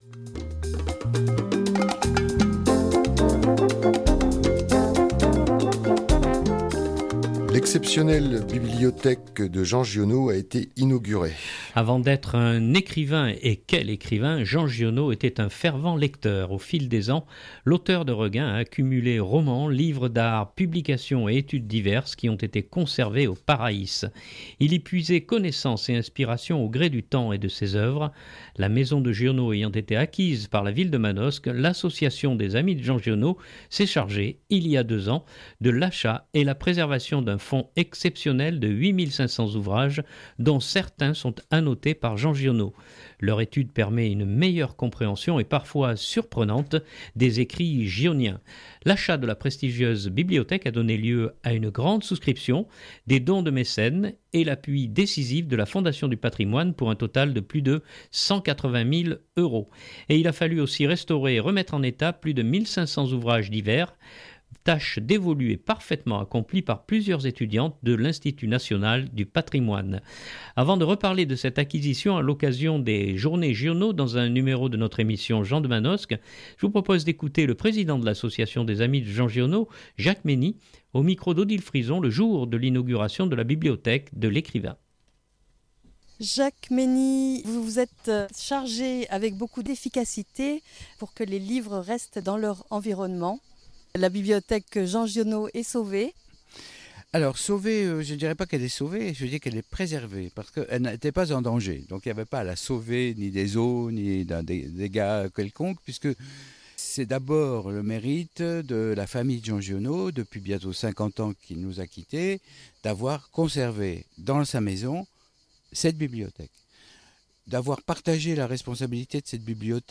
le jour de l’inauguration de la bibliothèque de l’écrivain